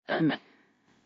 Add Juni Mission Voice Files